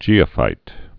(jēə-fīt)